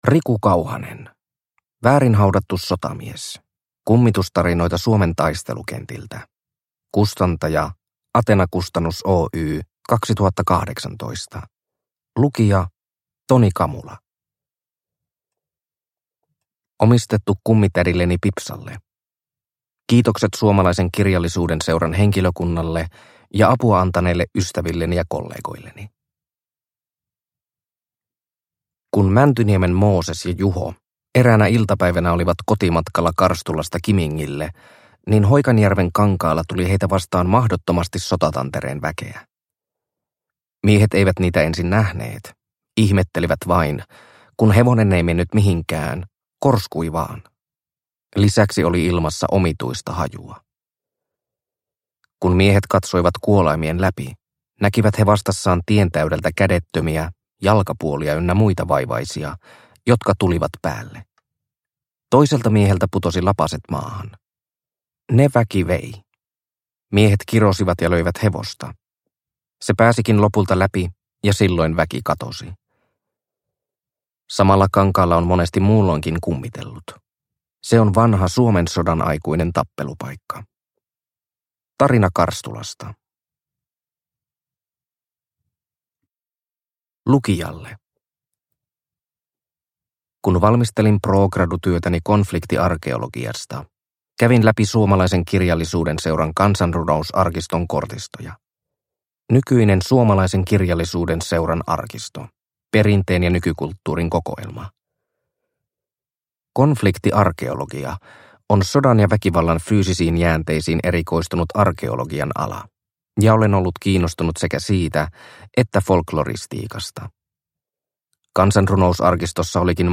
Väärin haudattu sotamies – Ljudbok – Laddas ner